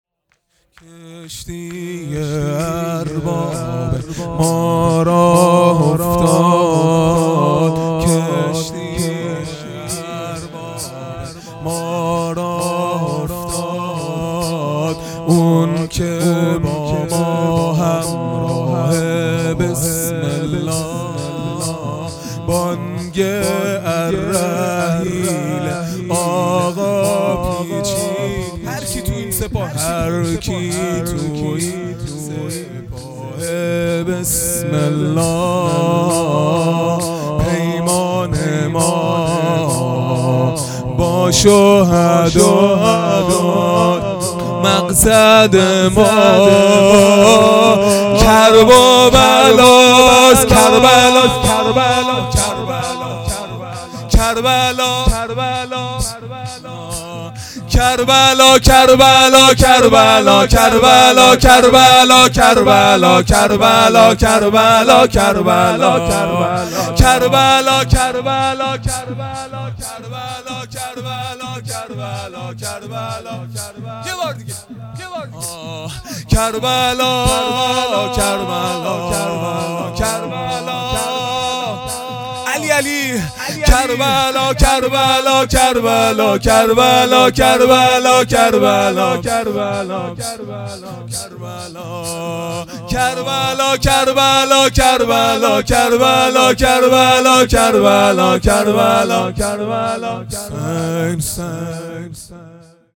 خیمه گاه - هیئت بچه های فاطمه (س) - شور ابتدایی | کشتی ارباب ما راه افتاد
دهه اول محرم الحرام ۱۴۴٢ | شب سوم